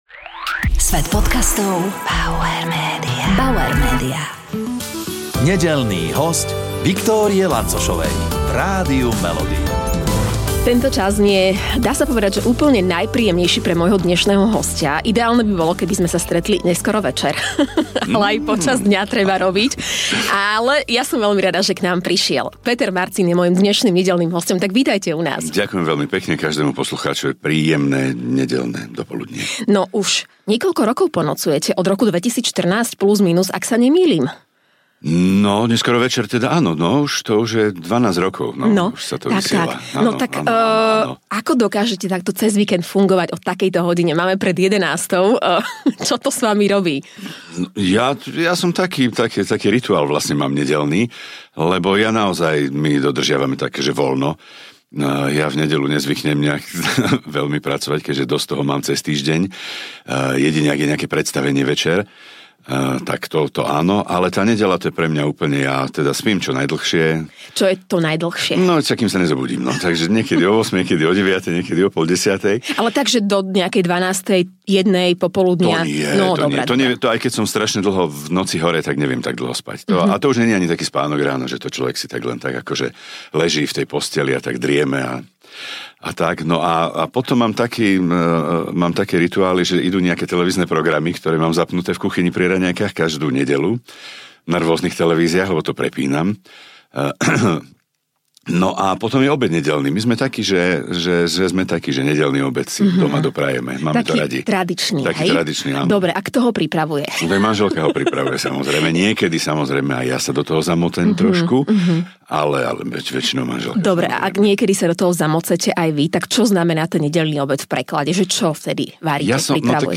v štúdiu rádia Melody